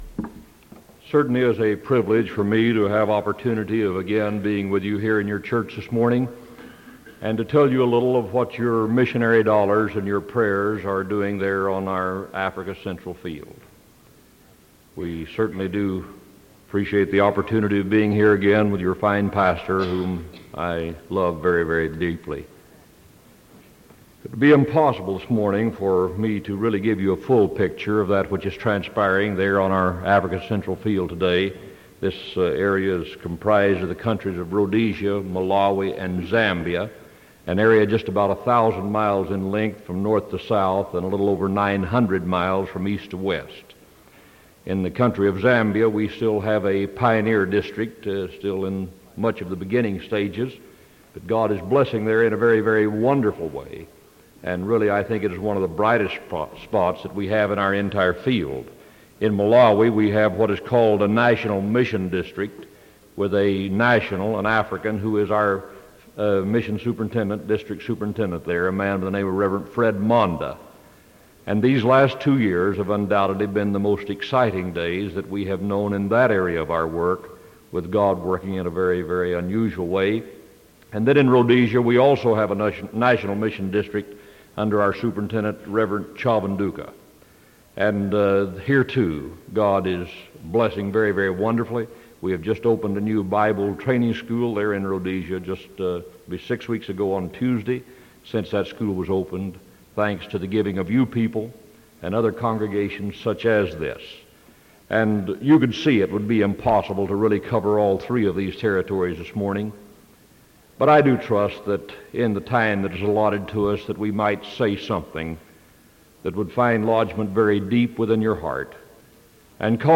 Sermon April 20th 1975 AM